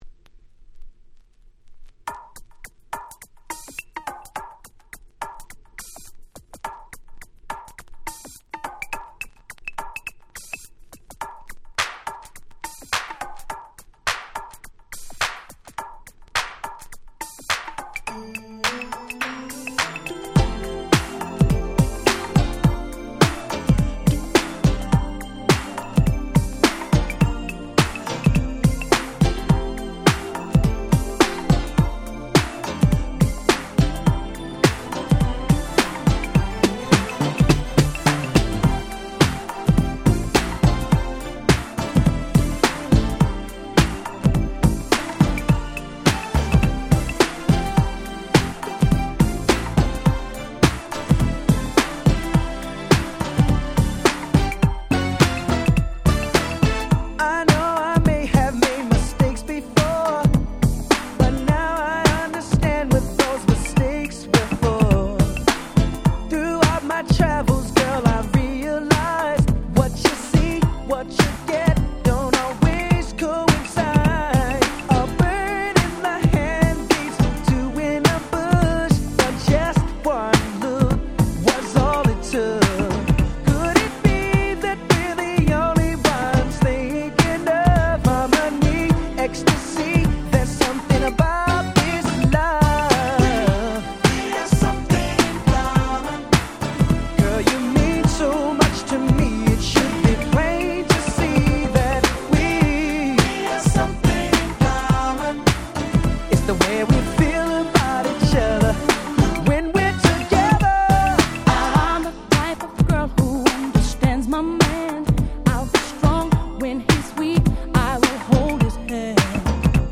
93' New Jack Swing / R&B Classics !!